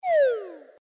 sfx_die.mp3